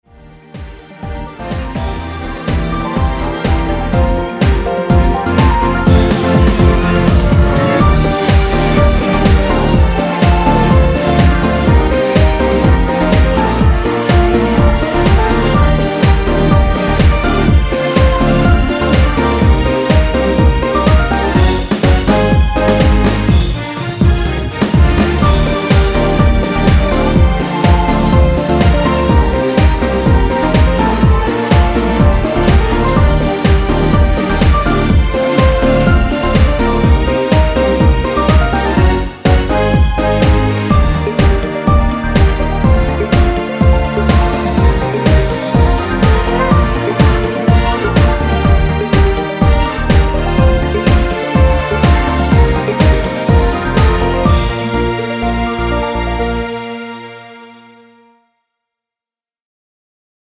후반부 바다 애드립은 코러스가 아니라 안들어가있습니다.(미리듣기참조)
엔딩이 페이드 아웃이라 엔딩을 만들어 놓았습니다.
원키에서(-2)내린 코러스 포함된 MR입니다.
Bb
앞부분30초, 뒷부분30초씩 편집해서 올려 드리고 있습니다.
중간에 음이 끈어지고 다시 나오는 이유는